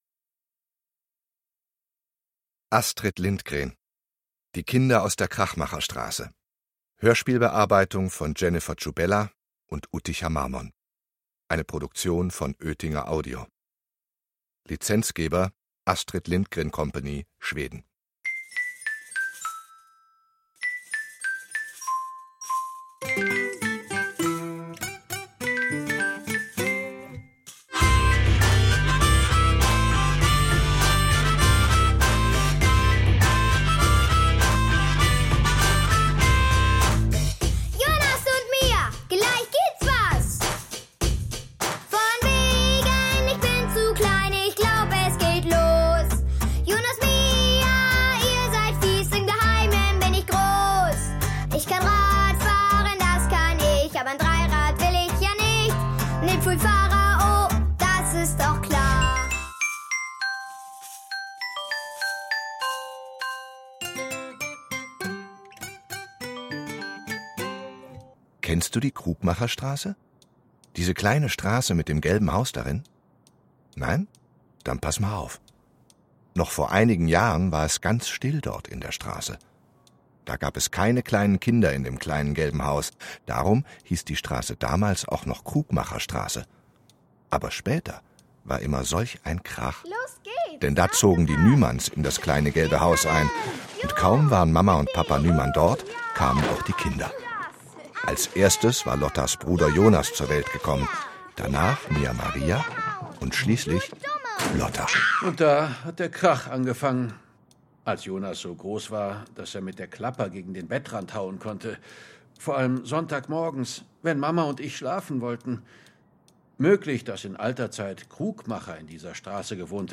Hörbuch: Die Kinder aus der Krachmacherstraße.